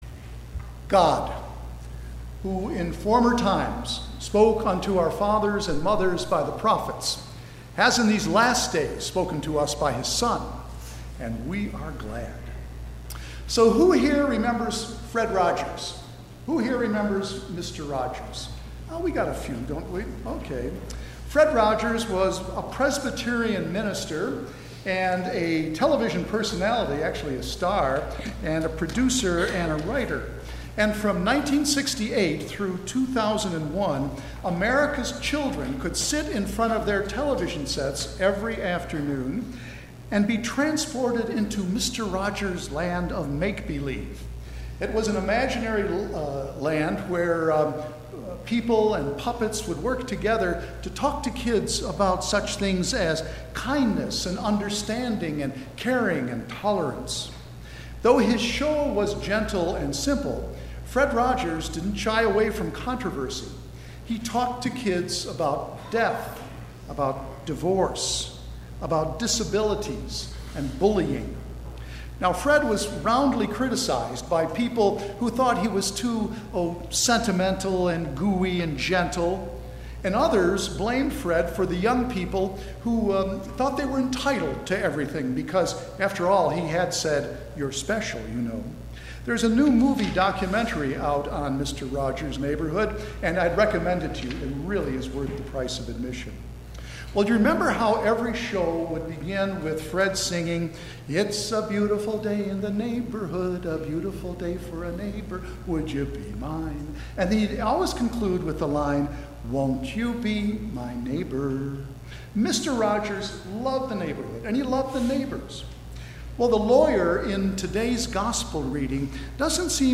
Sermon - 8/26/2018 - Wheat Ridge Lutheran Church, Wheat Ridge, Colorado
Thirteenth Sunday after Trinity
Sermon – 8/26/2018